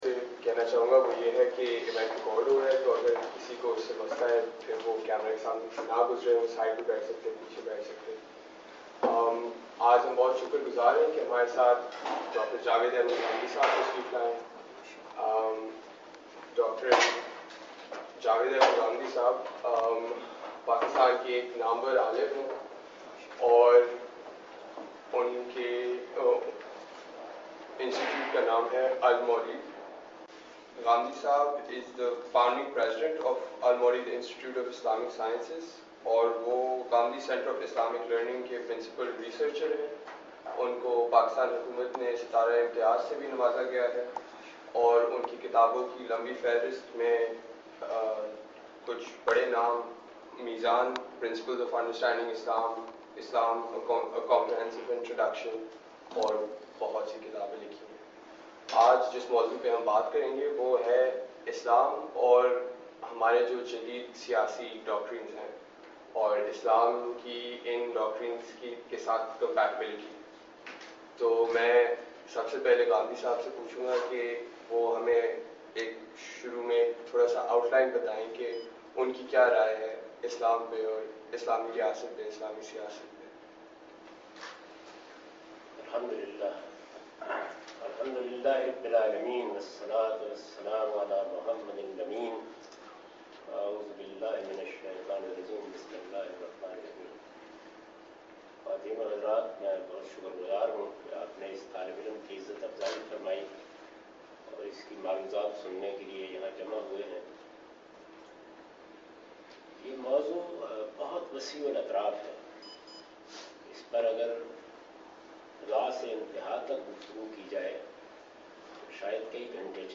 Islam and Modern Politics - Q&A with Javed Ghamidi at Kebel College, Oxford University